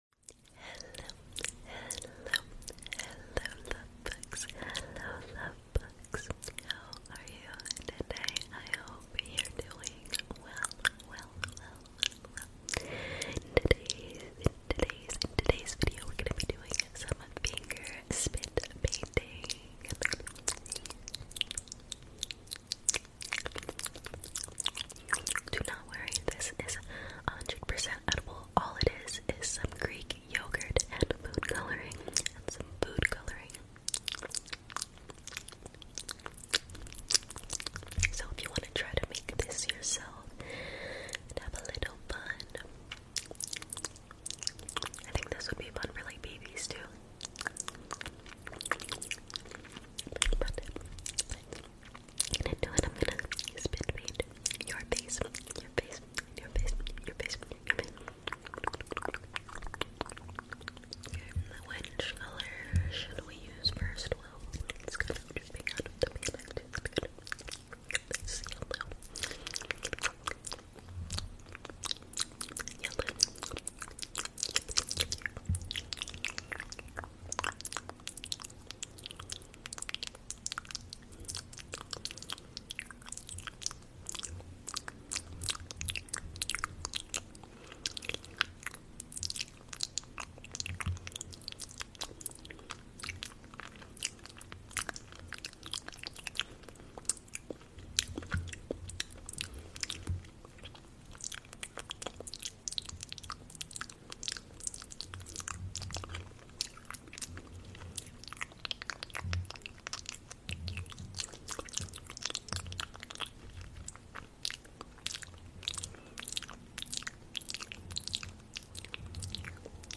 ASMR | Edible Spit Painting Your Face 🎨 Mouth Sounds